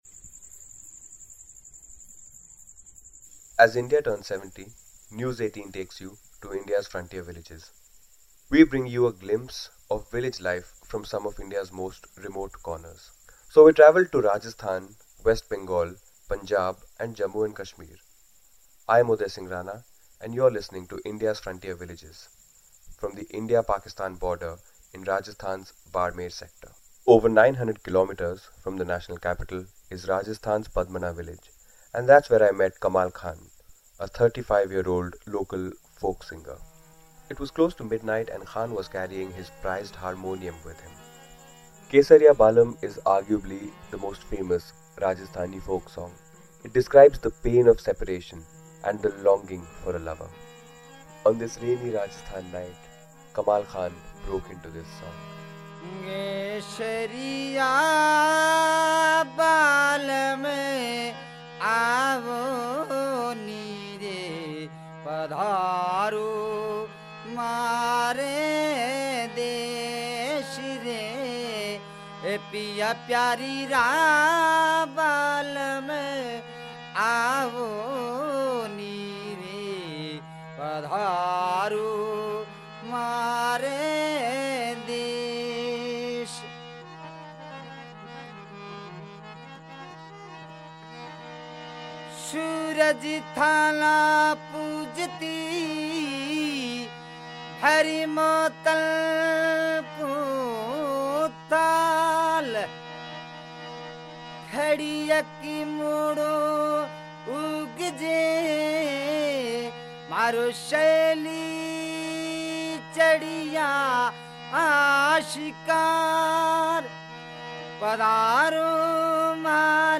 Indian folk musician
rustic folk songs of the desert